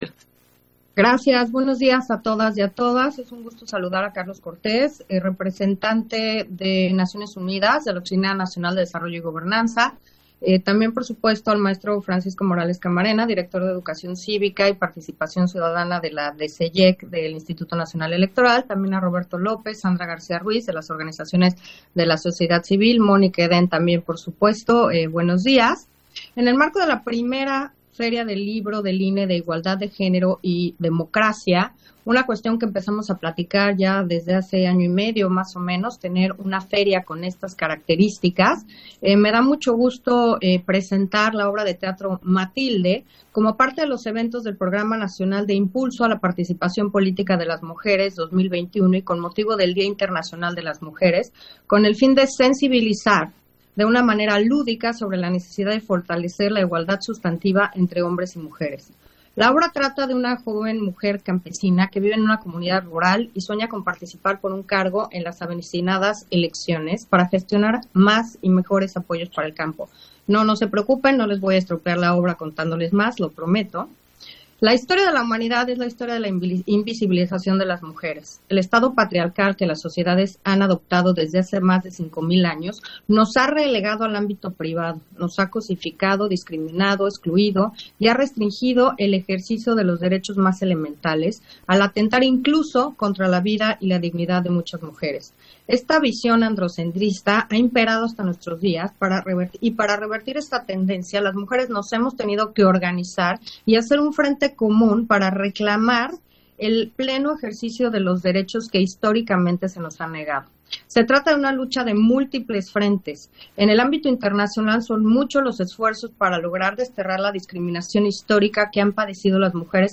Intervención de Carla Humphrey, en la presentación de la obra de teatro, Matilde, en el marco de la 1era. Feria del libro INE, Igualdad de Género y Democracia